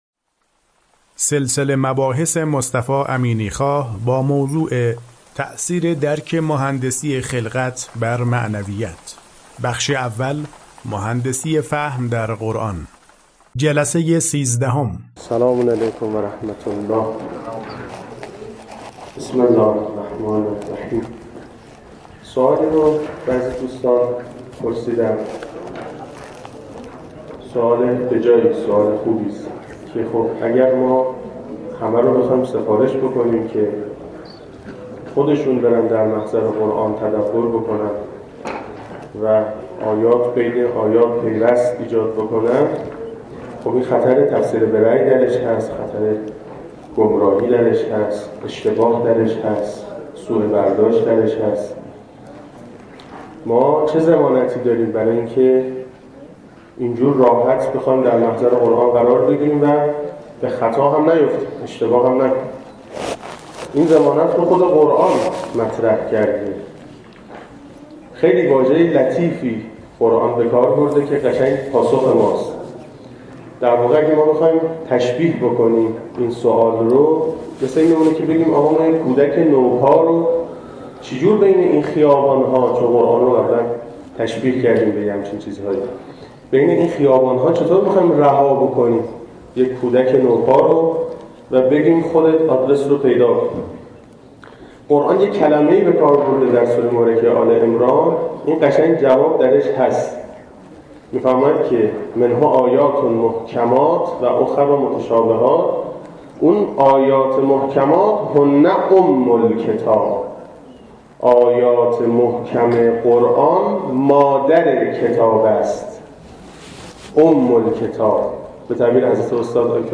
سلسله مباحث مهندسی خلقت که در دانشکده مهندسی دانشگاه فردوسی ارائه شده در چند بخش پیگیری می شود که شمای کلی آن بدین شرح است: